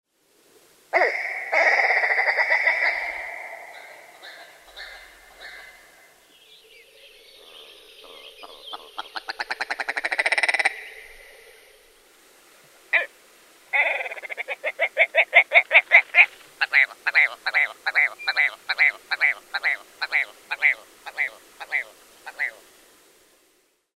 Riekko